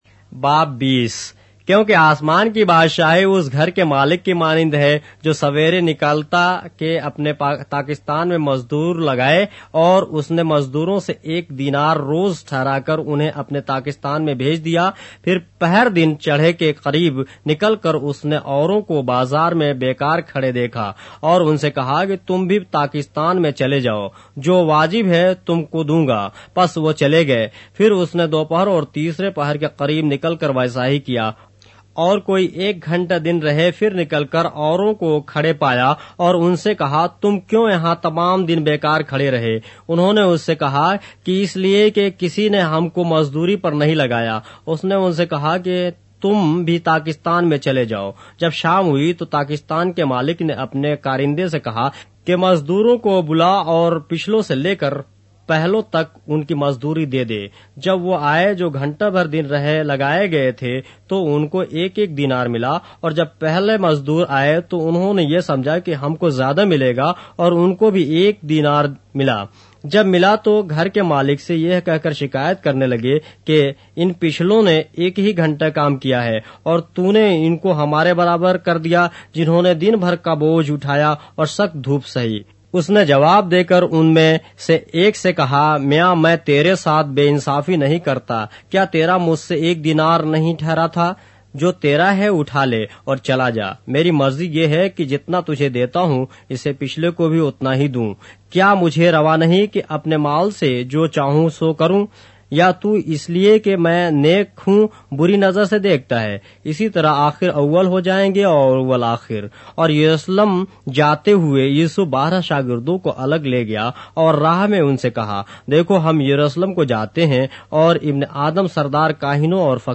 اردو بائبل کے باب - آڈیو روایت کے ساتھ - Matthew, chapter 20 of the Holy Bible in Urdu